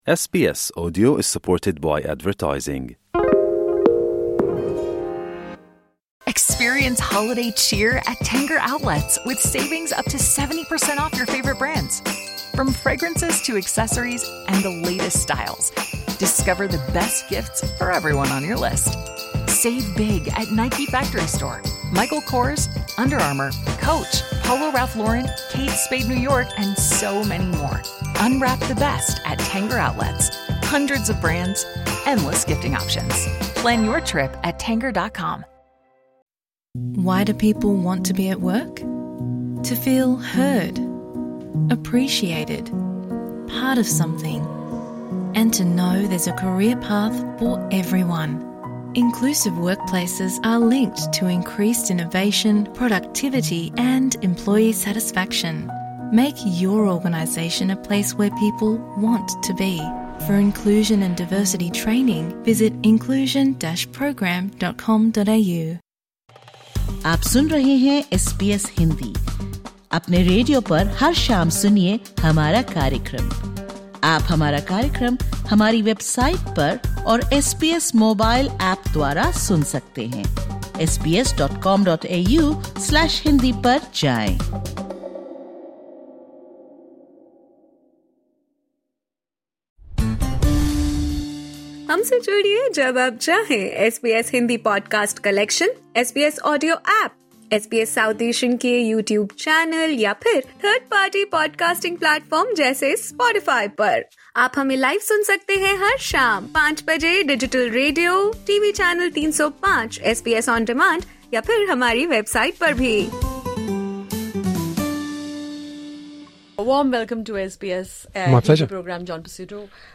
Victorian Opposition Leader John Pesutto recently visited the SBS studio In Melbourne. Listen to this podcast for his exclusive interview with SBS Hindi, where he discusses a range of issues, including the representation of candidates of Indian descent in the next state elections, his vision for making Victoria safe and inclusive, state's recent strategy to engage with and improve trade relations with India, and his action plan for international students.